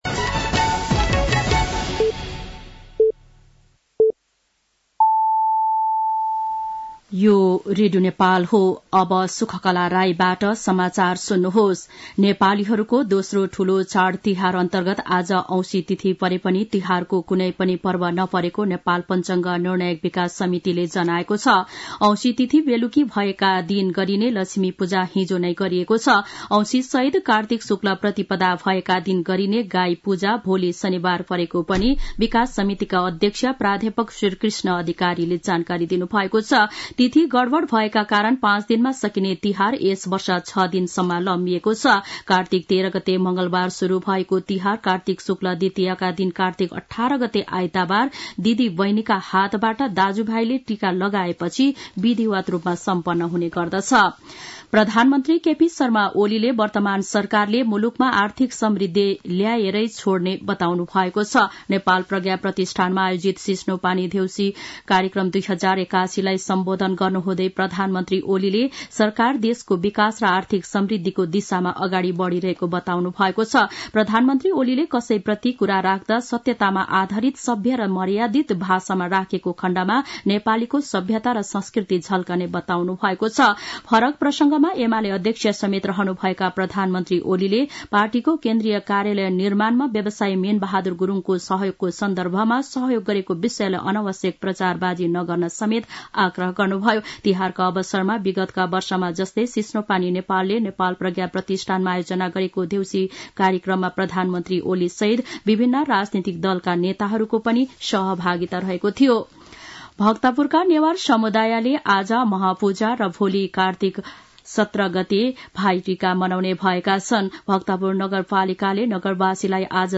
साँझ ५ बजेको नेपाली समाचार : १७ कार्तिक , २०८१
5-Pm-nepali-news-7-16.mp3